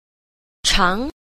3. 常人間 – cháng rénjiān – thường nhân gian (nhân gian)